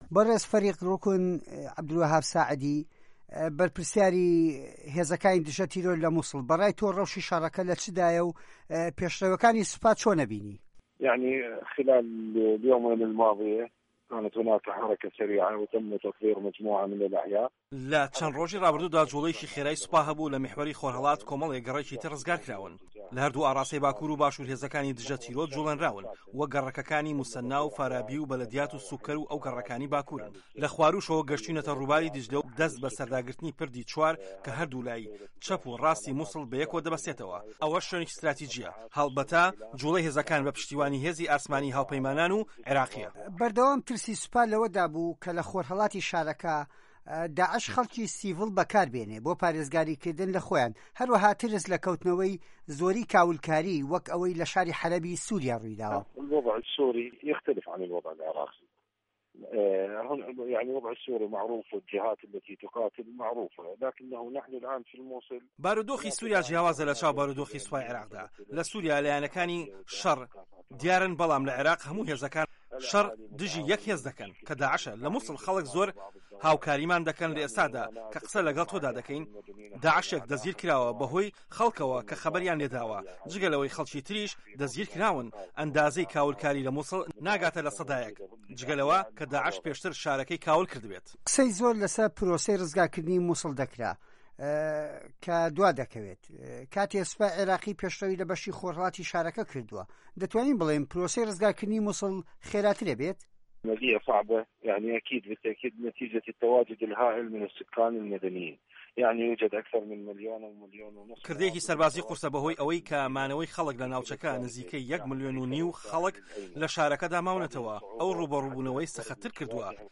وتووێژ لەگەڵ فه‌ریق ڕوکن عه‌بدولوه‌هاب ئه‌لساعدی